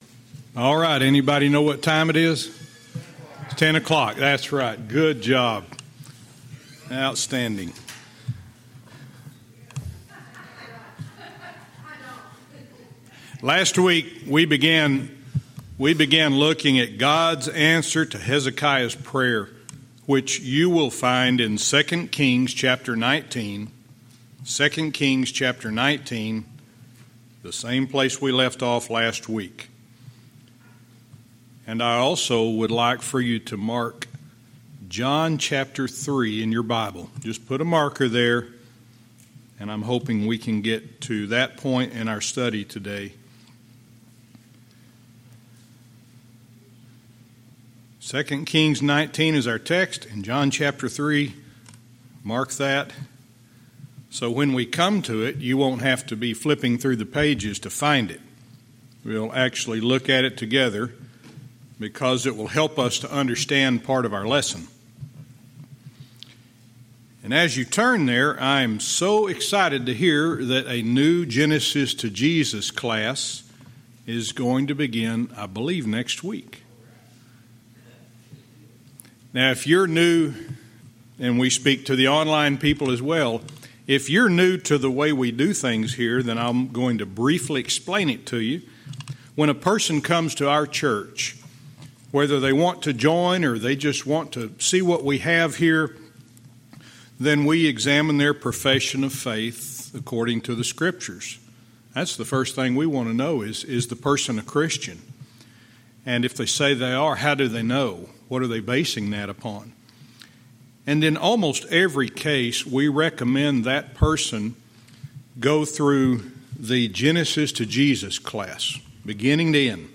Verse by verse teaching - 2 Kings 19:21-22